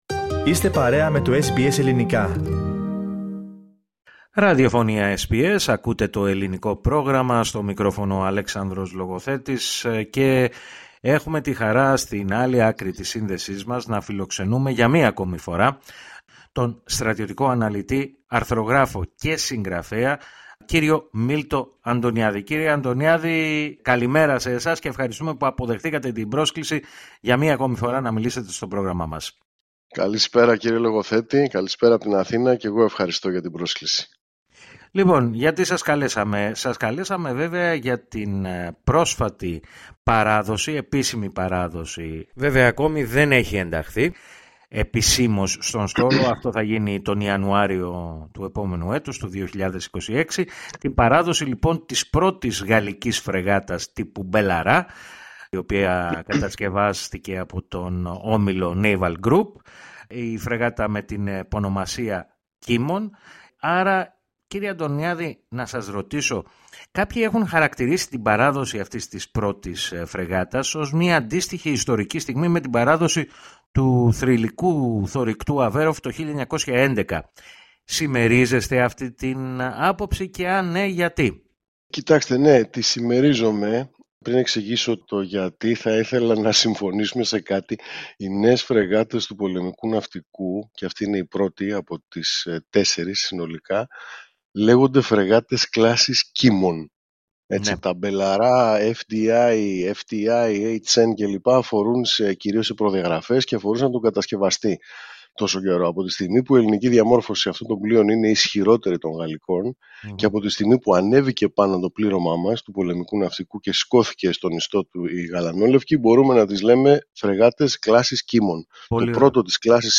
μίλησε στο Ελληνικό Πρόγραμμα της ραδιοφωνίας SBS, ο στρατιωτικός αναλυτής, αρθρογράφος και συγγραφέας,